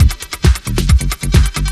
TECHNO125BPM 3.wav